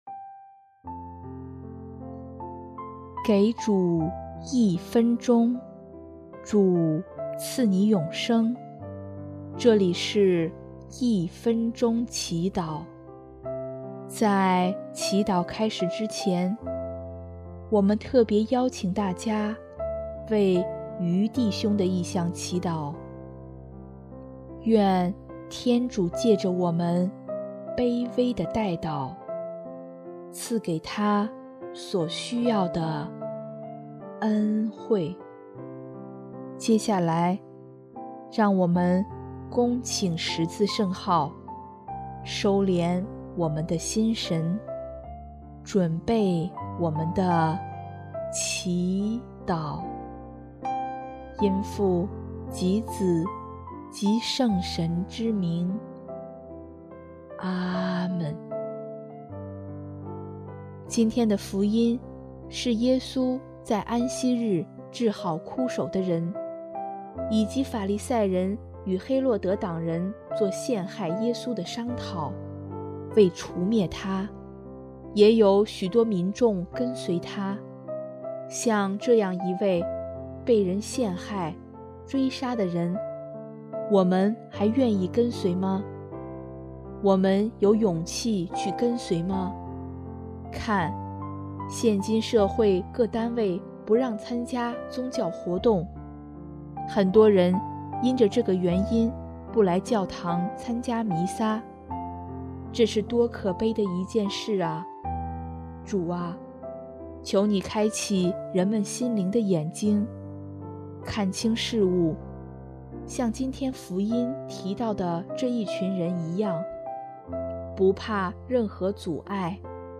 【一分钟祈祷】|1月23日 跟随